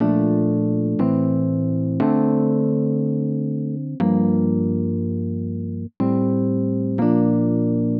Rhodes Stack 120 Bpm .wav